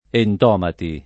entomo [ $ ntomo ] s. m. — ant. grecismo per «insetto», col pl. entomata [ ent 0 mata ] o più prob. antomata [ ant 0 mata ] in Dante, entomi [ $ ntomi ] e entomati [ ent 0 mati ] in autori più recenti